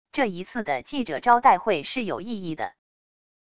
这一次的记者招待会是有意义的wav音频生成系统WAV Audio Player